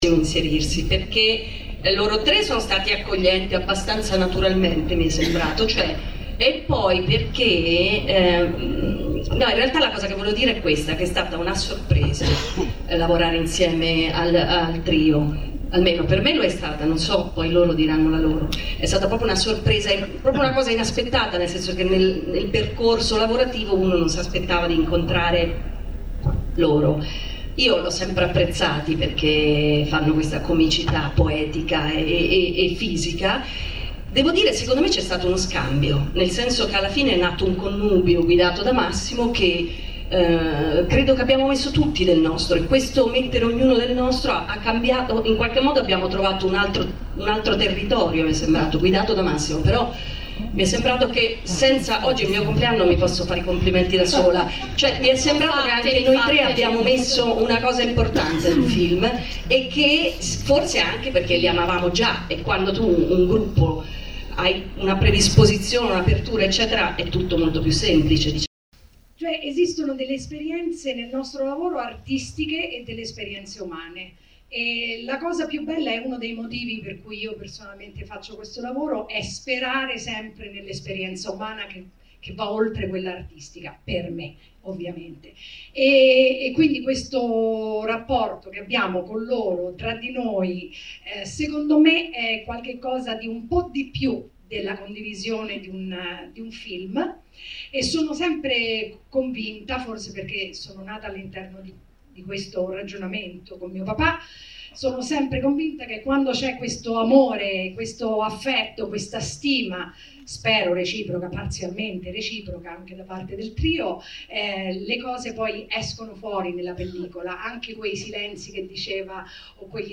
Le tre attrici raccontano di essere state accolte come in una famiglia, creando sul set una fantastica atmosfera. Sentiamo i racconti di Lucia Mascino e Carlotta Natoli: